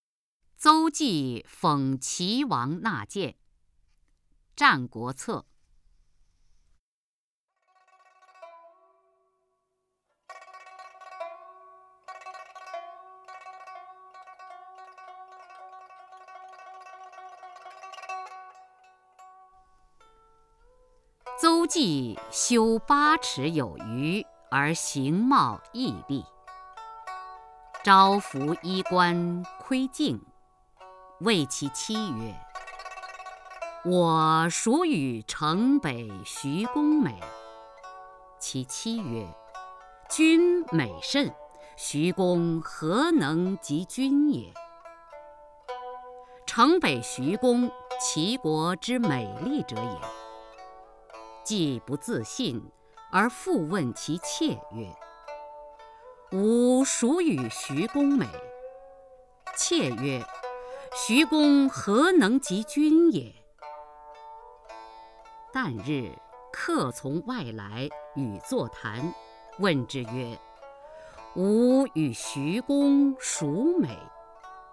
首页 视听 名家朗诵欣赏 雅坤
雅坤朗诵：《》(佚名)　/ 佚名